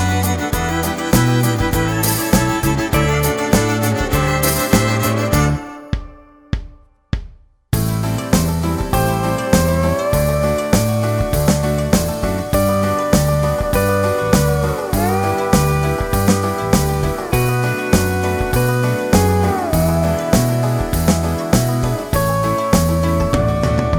no Backing Vocals Country (Male) 2:53 Buy £1.50